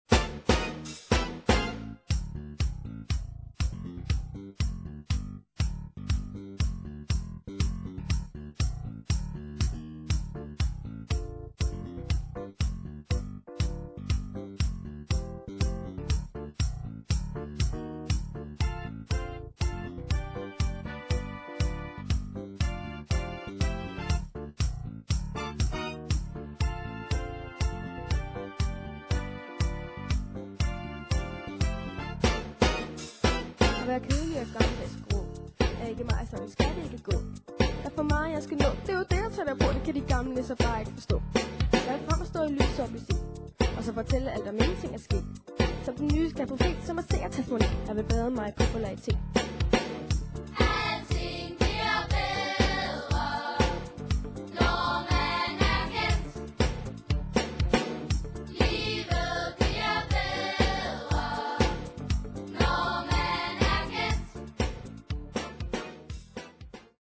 Derudover hører vi en stribe smukke og fængende ørehængere.